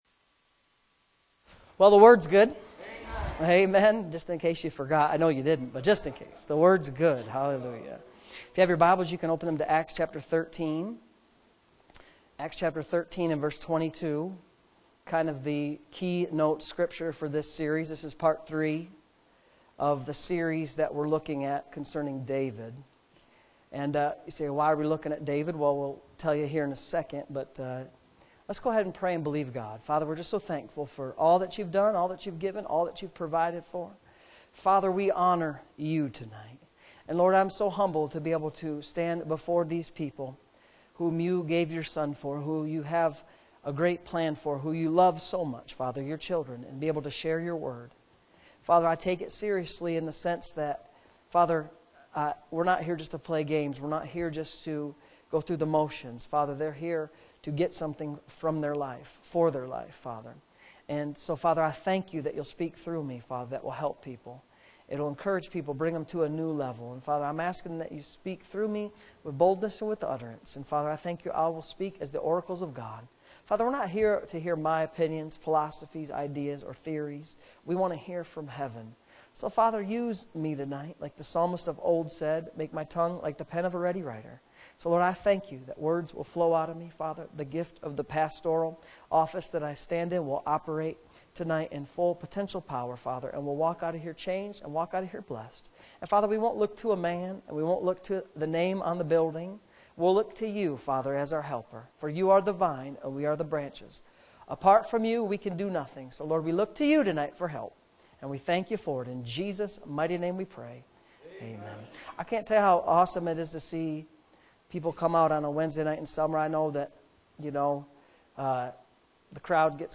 Wednesday Evening Services